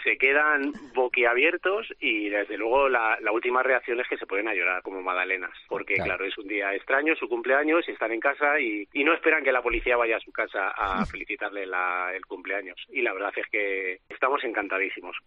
Sin duda, estos mensajes son los más especiales que han lanzado desde la megafonía de su coche patrulla.